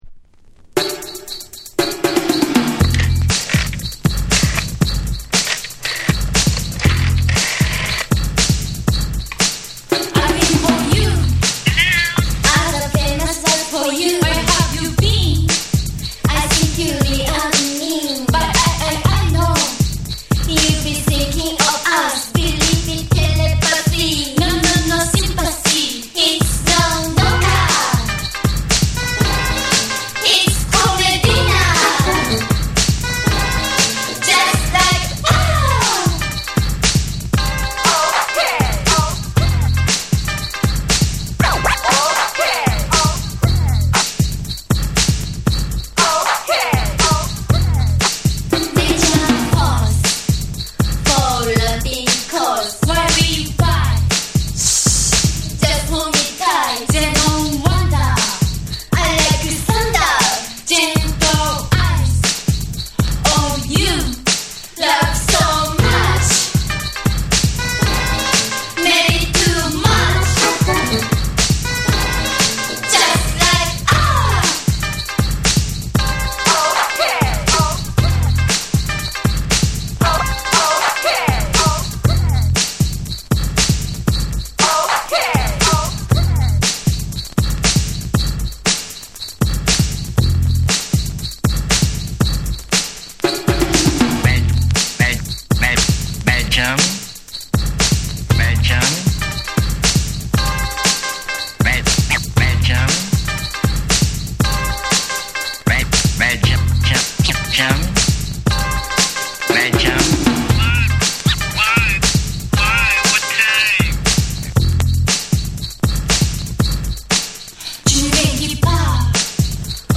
JAPANESE / BREAKBEATS